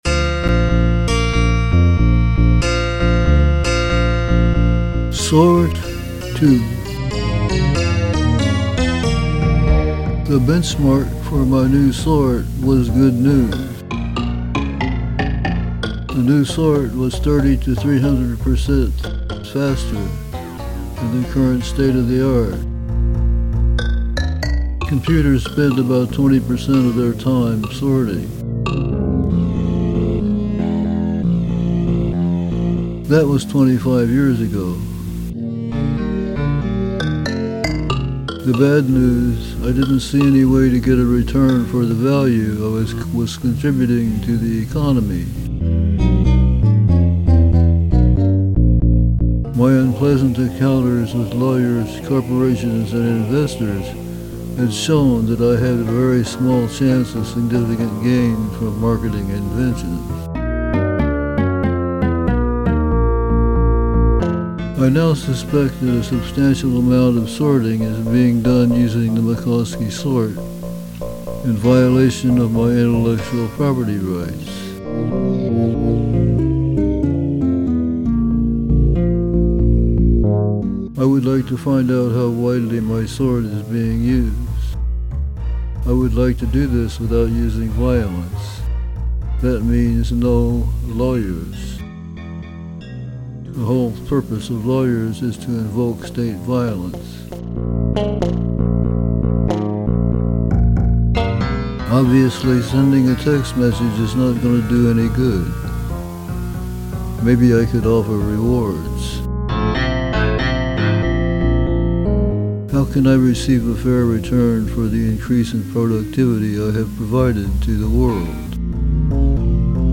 Sort2 Narration.mp3
Sort2_Narration.mp3